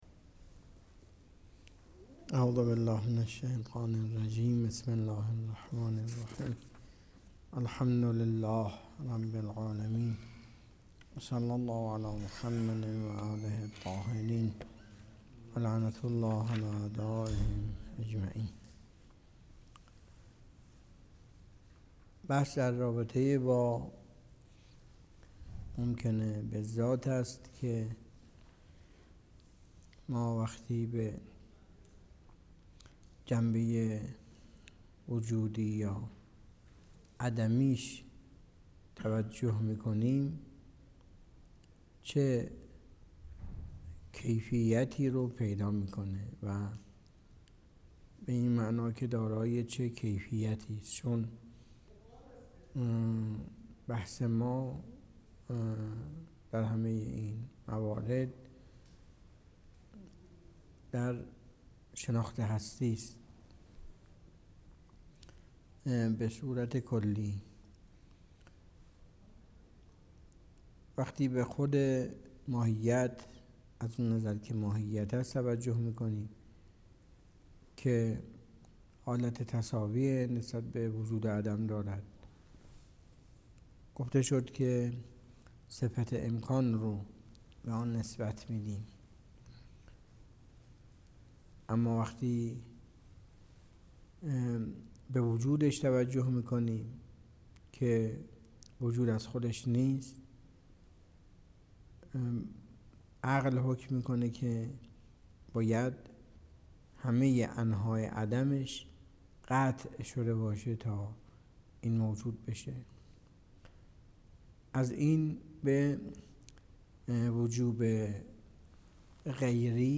درس فلسفه اسفار اربعه آیت الله تحریری 97.09.03